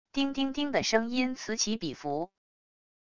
叮叮叮的声音此起彼伏wav音频